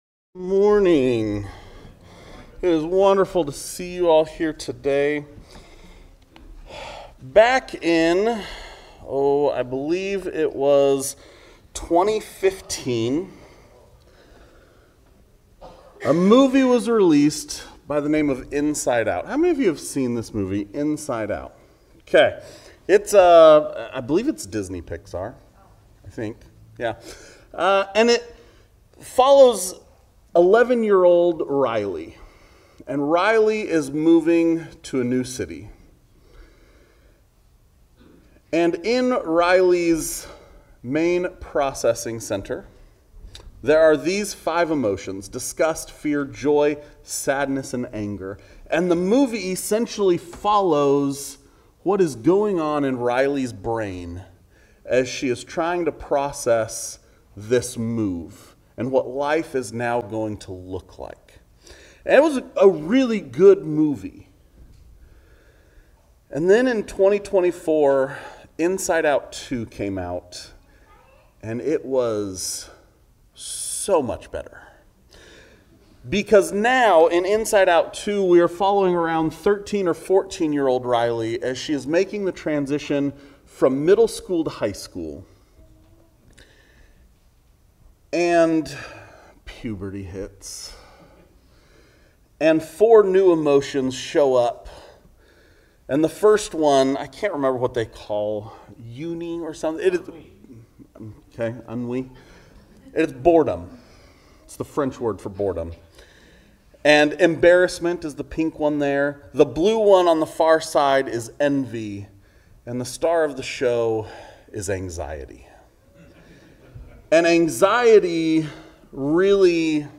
Join us for a timely message of hope as we unpack Jesus' teaching on worry from Matthew 6:25-34. This is more than a sermon—it's a heartfelt guide to trading your burdens for God's peace.